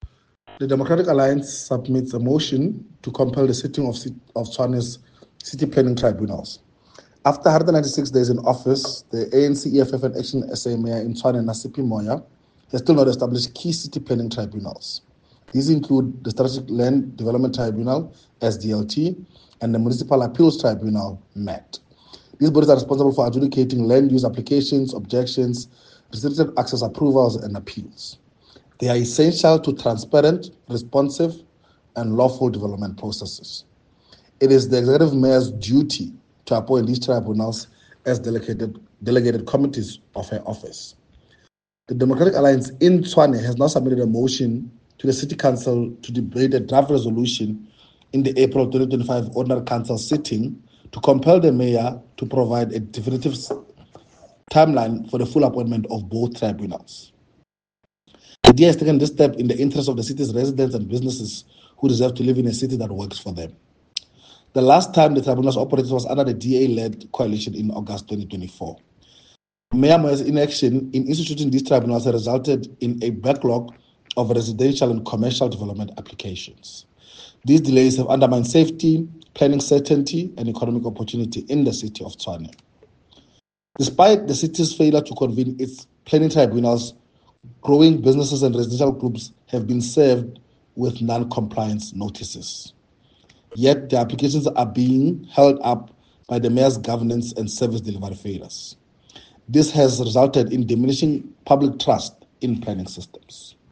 Note to Editors: Please find an English soundbite by Cllr Pogiso Mthimunye attached